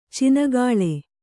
♪ cinagāḷe